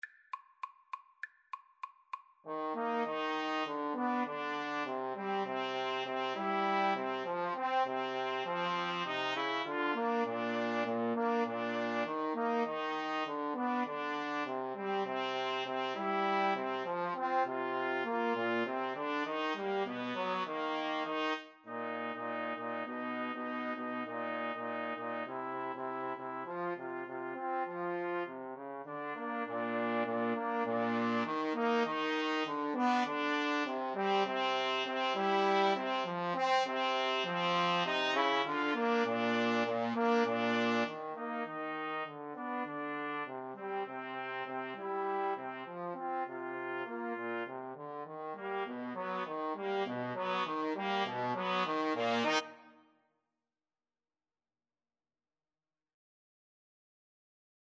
Quick Swing = c. 100
Jazz (View more Jazz 2-Trumpets-Trombone Music)